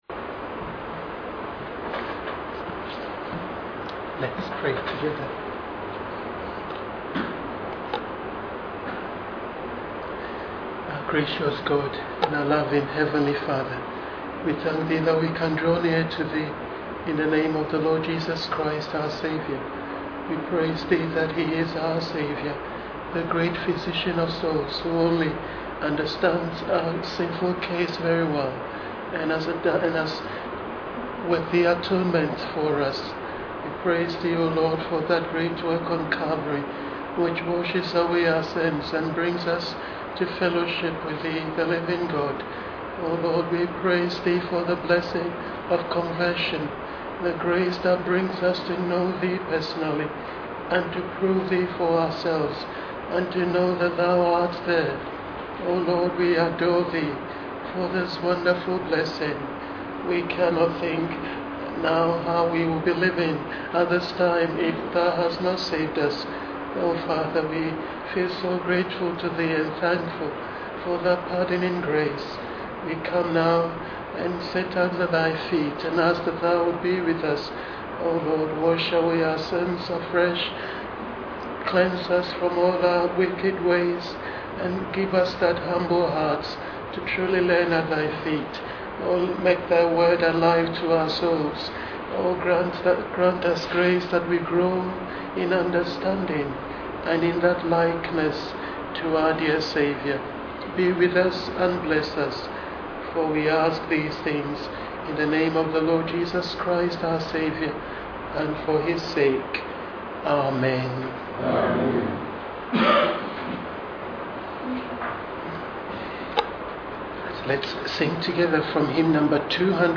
Special Marks of Christian Blessings. Ephesians 1:1 – Full Service